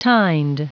Prononciation du mot tined en anglais (fichier audio)
Prononciation du mot : tined